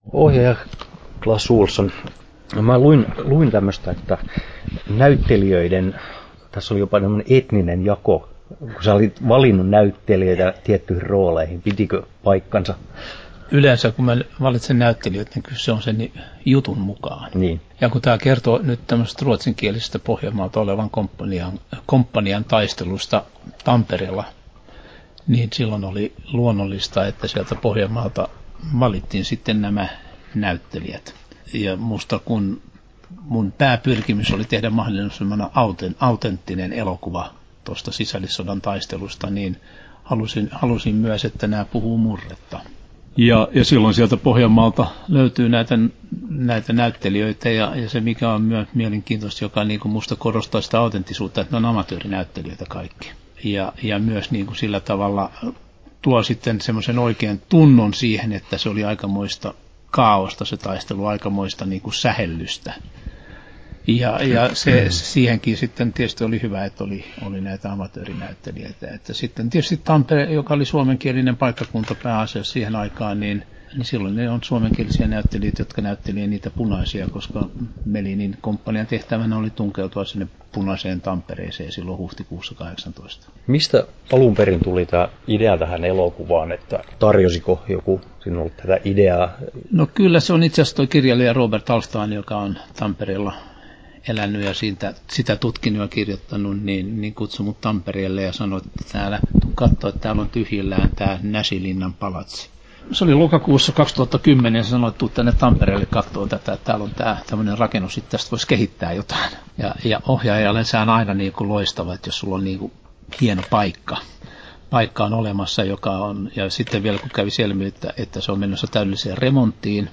Haastattelut
11'55" Tallennettu: 13.3.2012, Turku Toimittaja